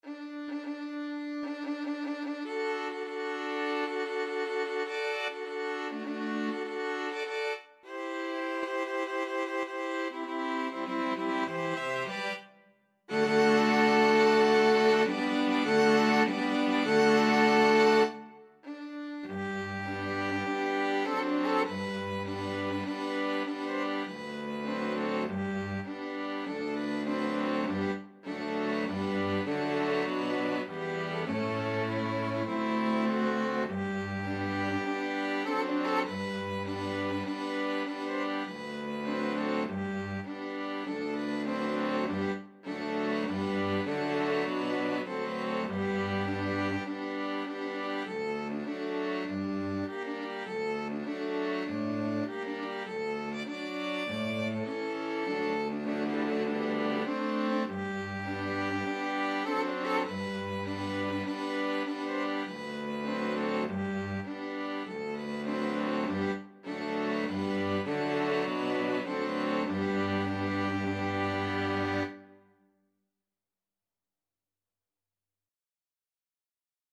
Classical Verdi, Giuseppe Grand March from Aida String Quartet version
Violin 1Violin 2ViolaCello
4/4 (View more 4/4 Music)
G major (Sounding Pitch) (View more G major Music for String Quartet )
Maestoso = c. 100
Classical (View more Classical String Quartet Music)
aida_march_STRQ.mp3